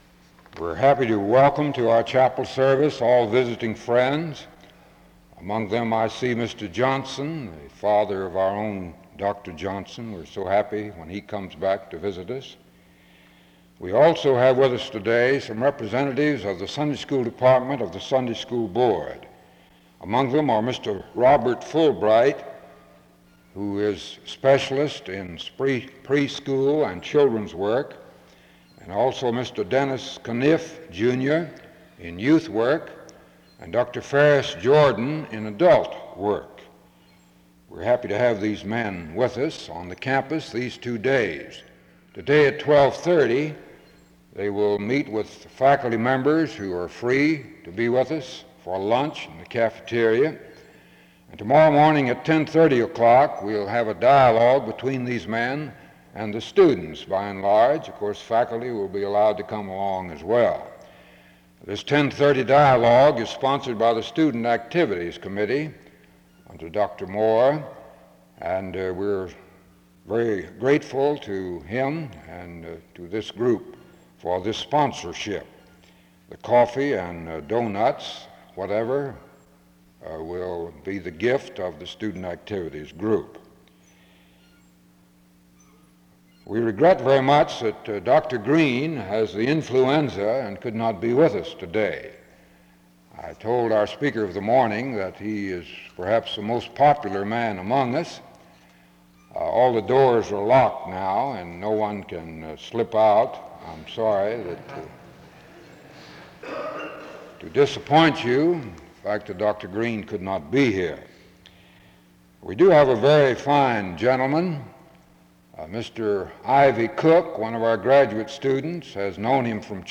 Location Wake Forest (N.C.)